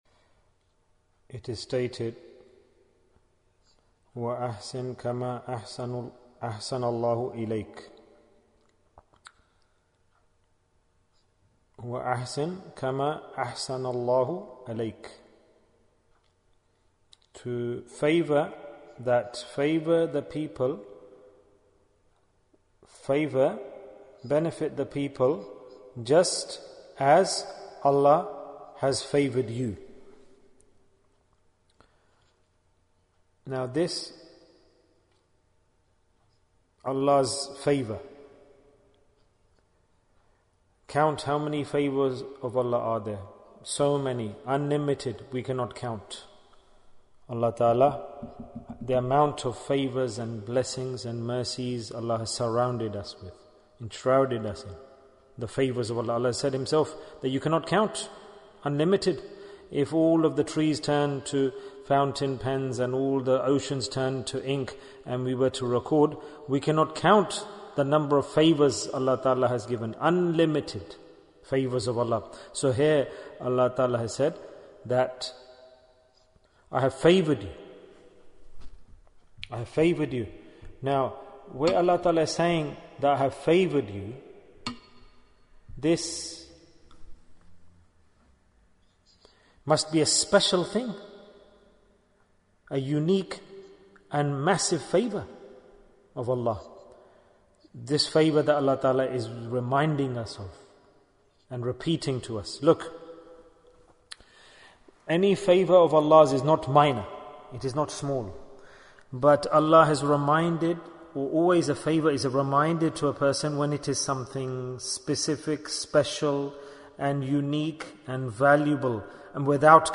The Message of Rabbi-ul-Awwal Bayan, 31 minutes29th October, 2020